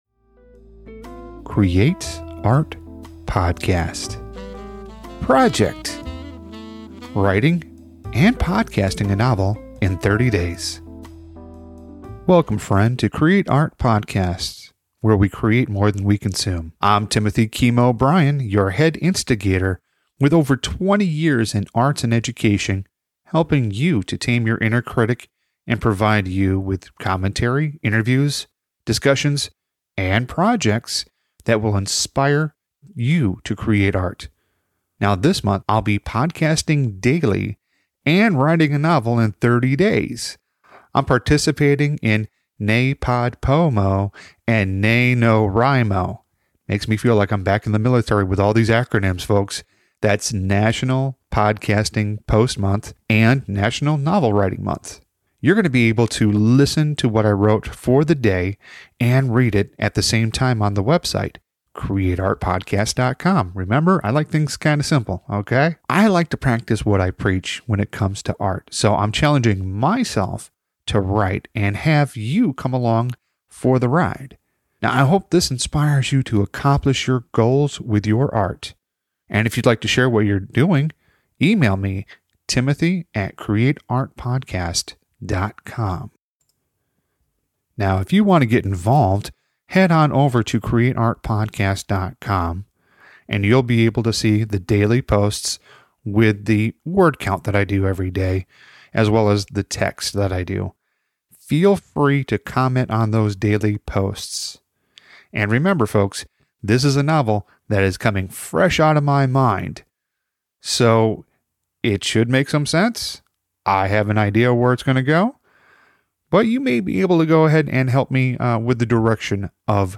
You will be able to listen to what I wrote for the day and read it at the same time.